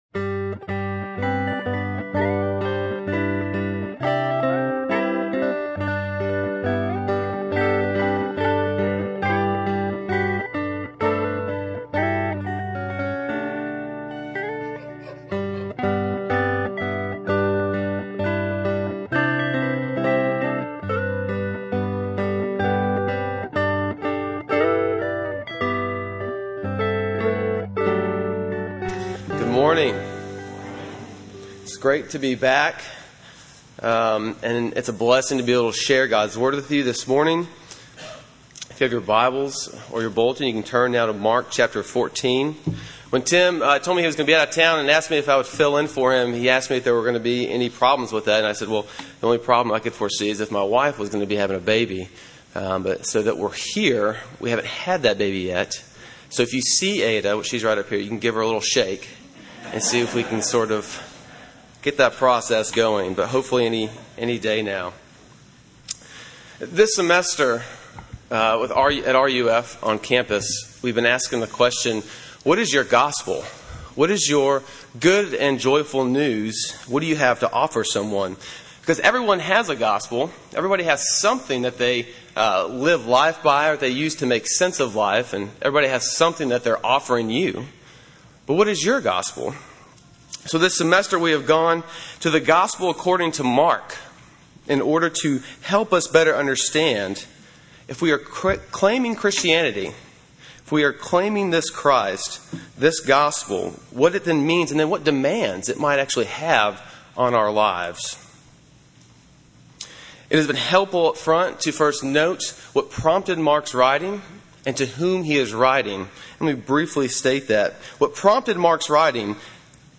Sermon on Mark 14:10-31 from May 3